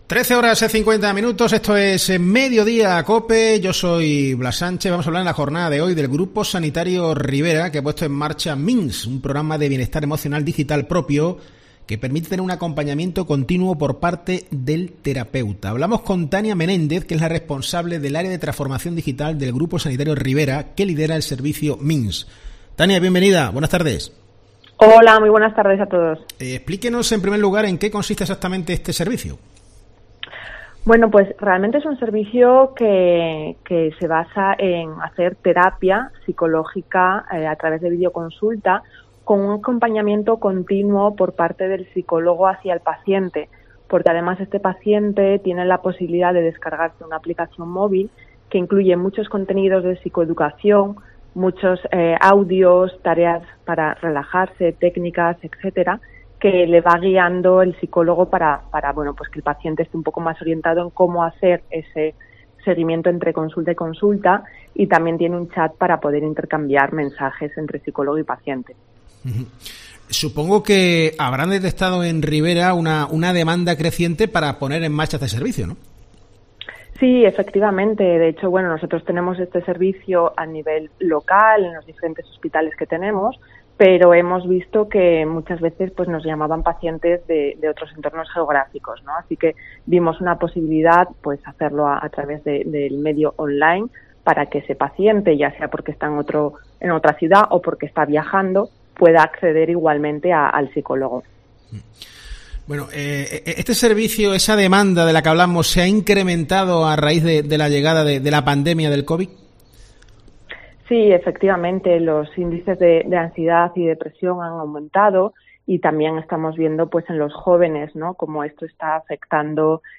En COPE hemos entrevistado